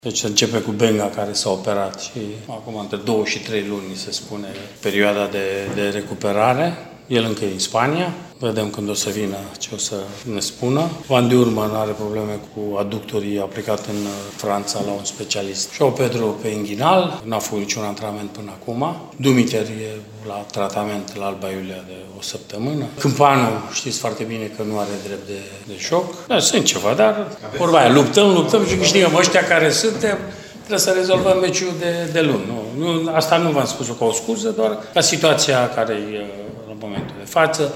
Problemele de efectiv sunt cele mai numeroase din această toamnă, iar unele dintre ele ar putea persista pe tot parcursul săptămânilor active din acest final de an. ”Principalul” Mircea Rednic, el însuși afectat încă de o răceală, a vorbit despre situațiile lui Benga, Van Durmen, Joao Pedro și Cîmpanu: